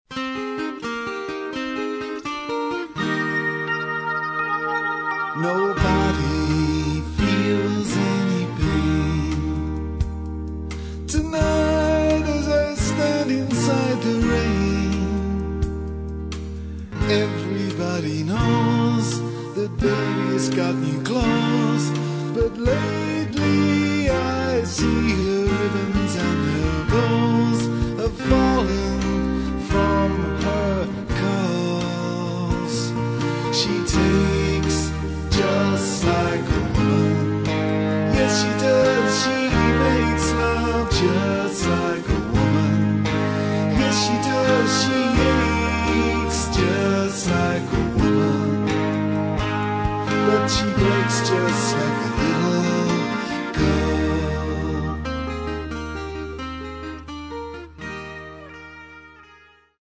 Рок
акустическая гитара, гитара, бас, клавиши, вокал